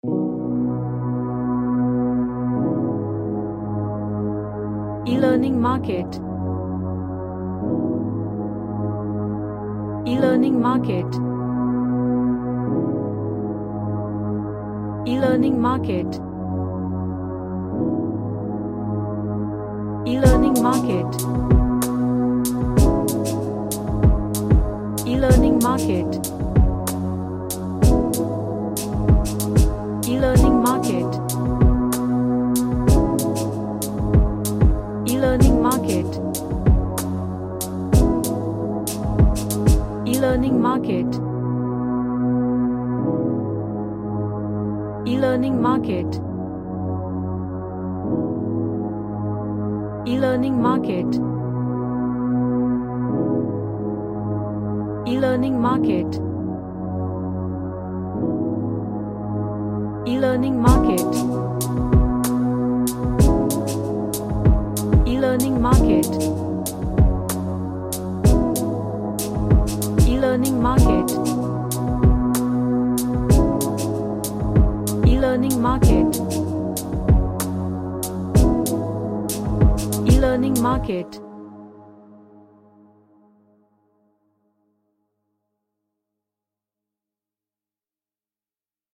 A ambient padded track with lots of Pads.
Relaxation / Meditation